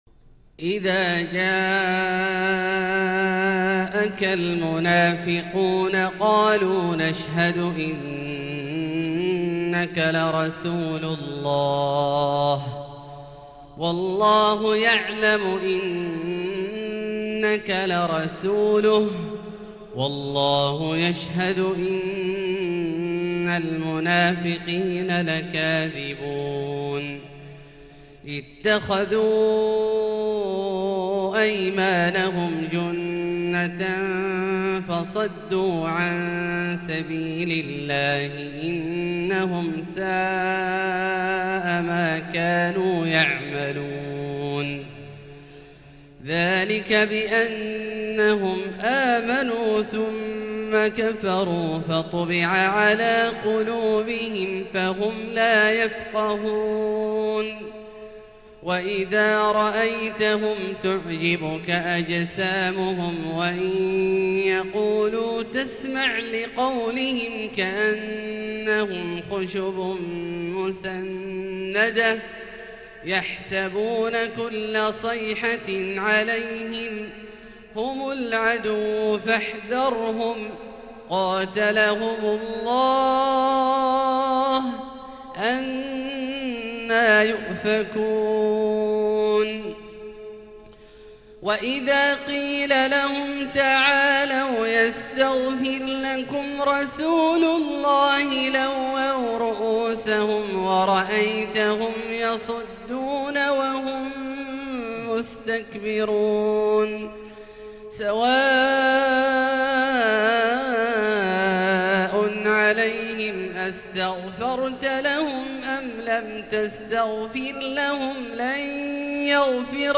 تلاوة شجية لـ سورة المنافقون كاملة للشيخ د. عبدالله الجهني من المسجد الحرام | Surat Al-Munafiqun > تصوير مرئي للسور الكاملة من المسجد الحرام 🕋 > المزيد - تلاوات عبدالله الجهني